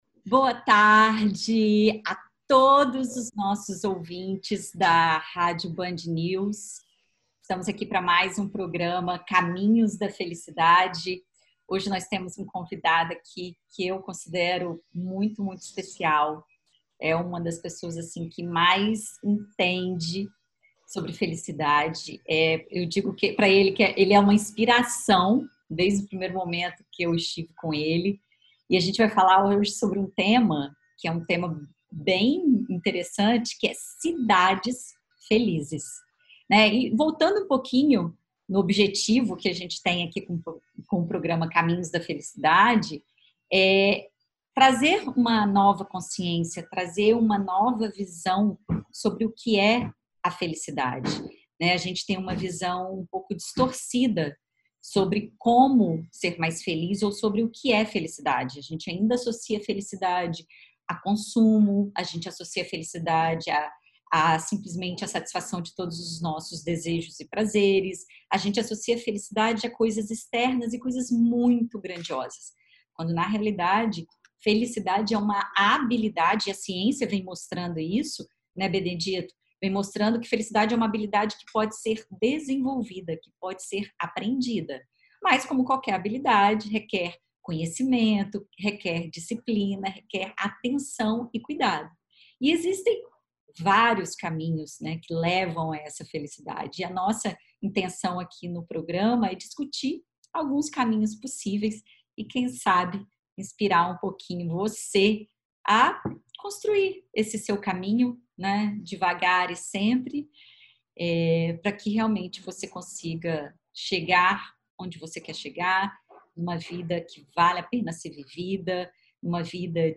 No bate papo, eles chamam a atenção para características dos lugares que possuem políticas públicas voltadas para o bem estar e para a felicidade das pessoas.